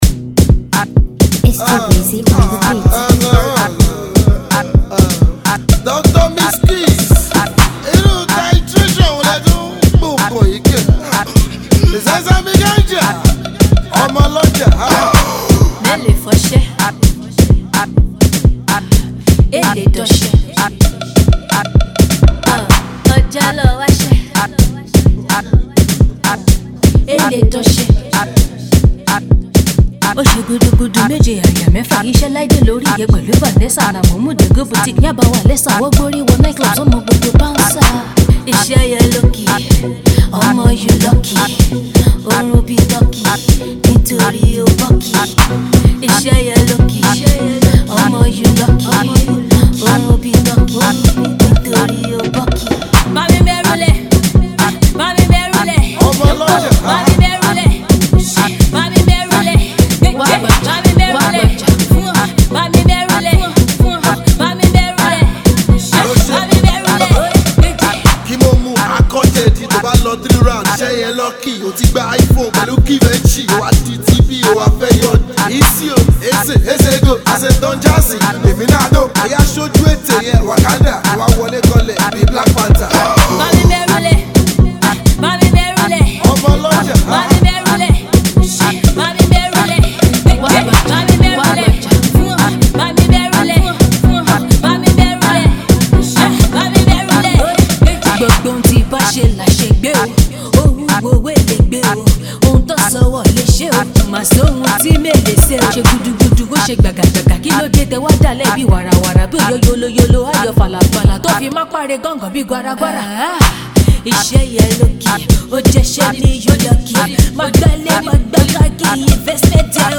Shaku Shaku
banging